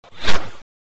[Track 08] Whoosh Sound Effect.wav